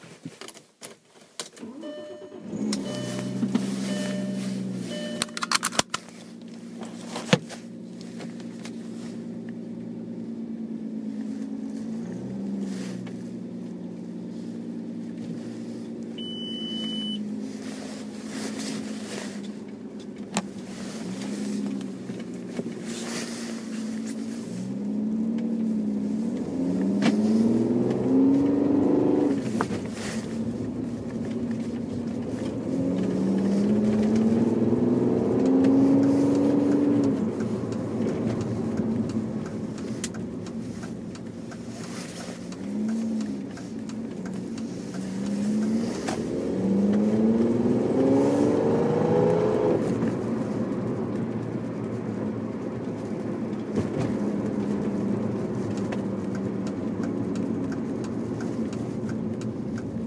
Field Recording #3
location: in my car, pulling out of the driveway and down my street
sounds heard: car starting, beeping, engine revving, coat swishing, blinker gong